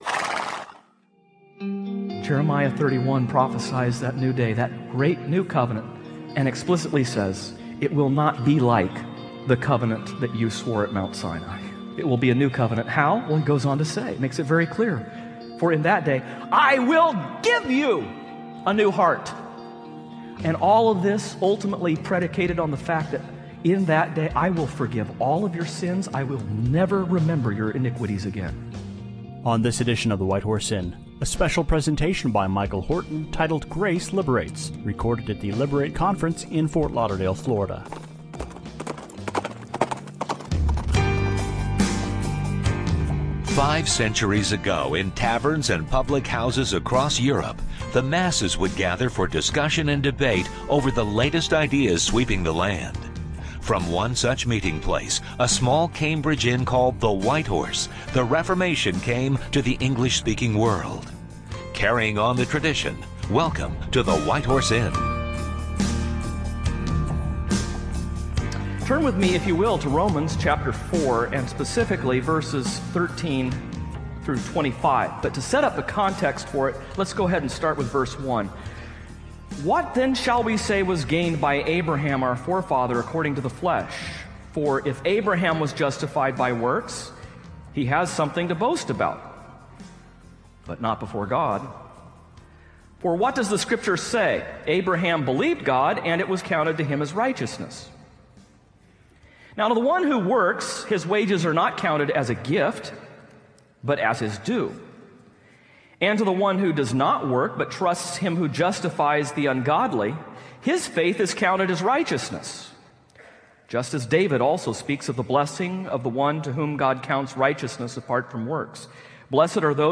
Romans Event(s): Liberate Conference Topic(s